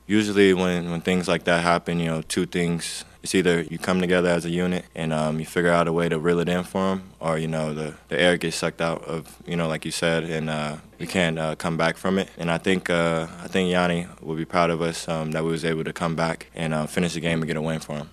Porter talked about the message to the team when Antetokounmpo went down.